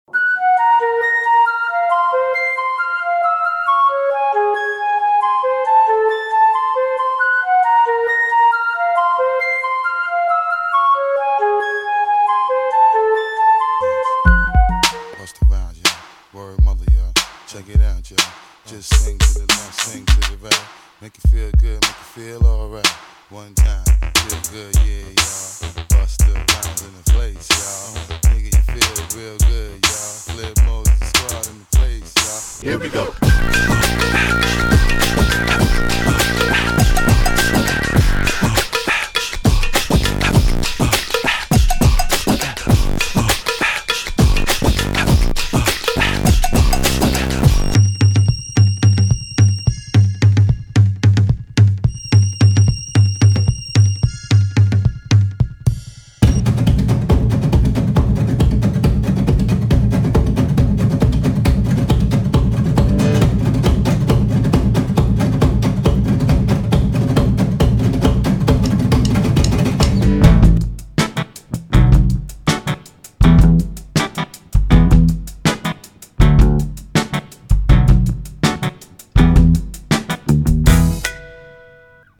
Here’s a medley of intros to some songs I listened to this week: